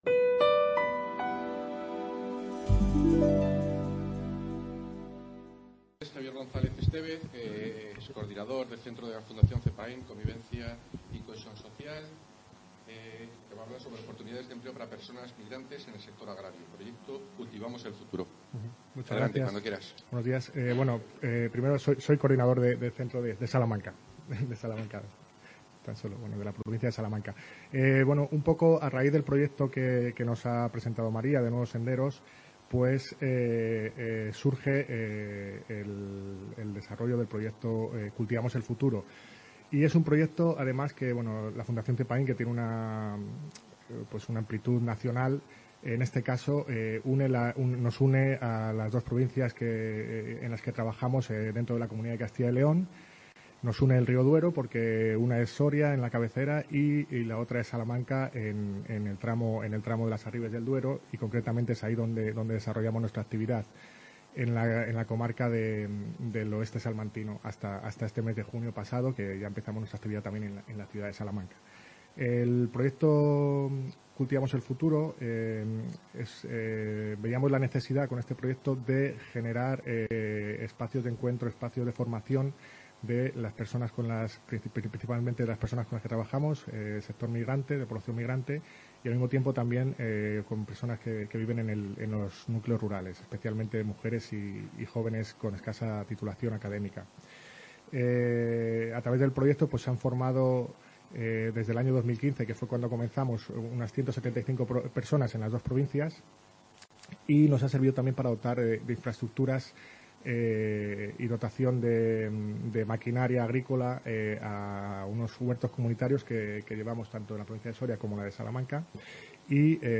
CA Ponferrada - Taller de la Red Rural Nacional sobre Desarrollo Rural Inclusivo